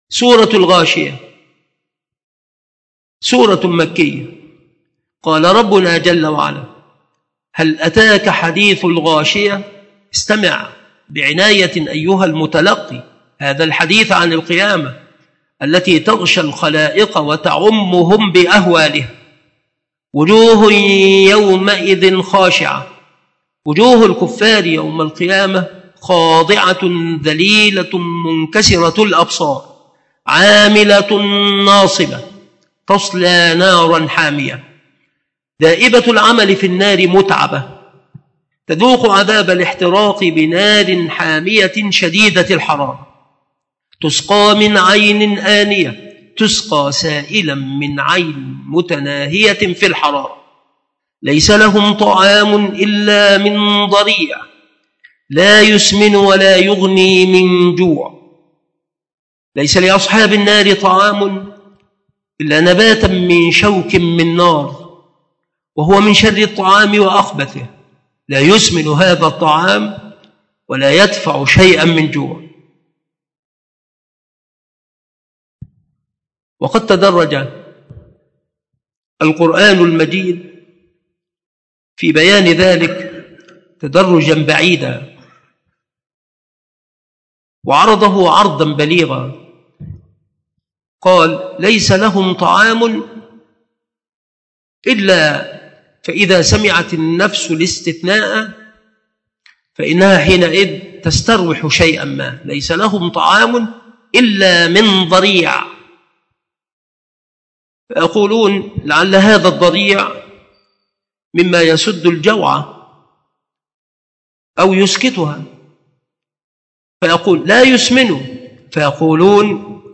مكان إلقاء هذه المحاضرة بالمسجد الشرقي بسبك الأحد - أشمون - محافظة المنوفية - مصر